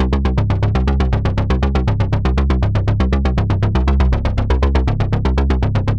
Index of /musicradar/dystopian-drone-samples/Droney Arps/120bpm
DD_DroneyArp4_120-C.wav